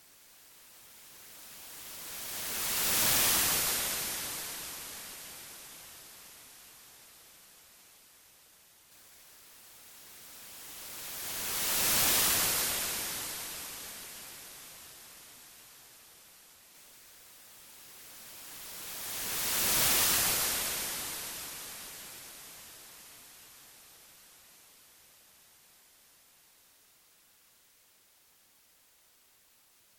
Seawash